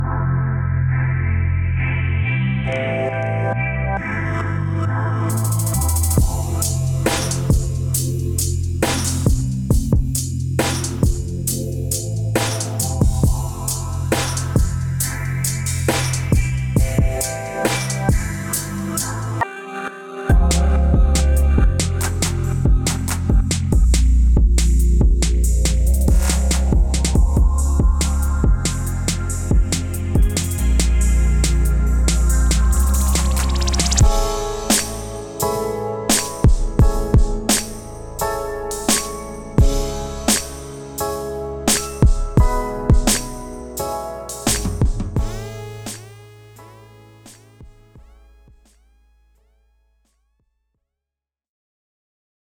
探索了在全世界图表中占主导地位的EDM，极简和低速节奏氛围。
还包括完整的打击杆和击打音轨，以提供完整的拍子灵活性。